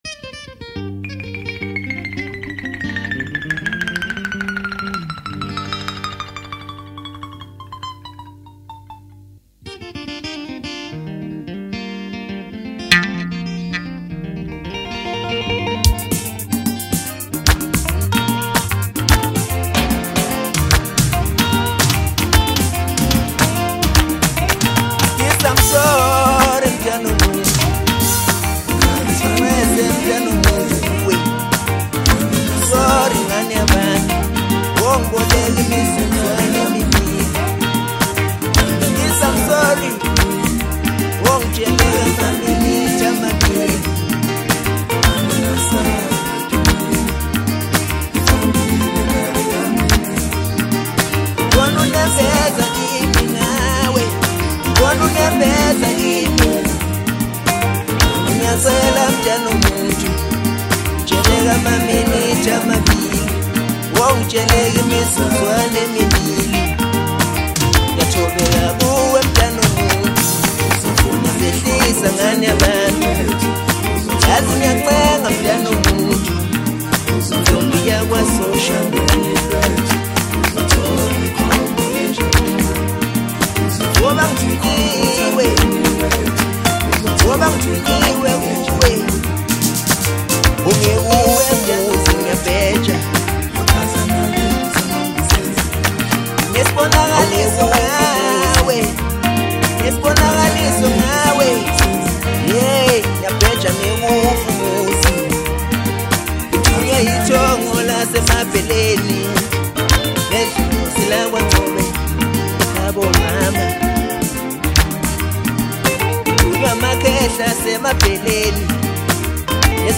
December 29, 2025 admin Maskandi 0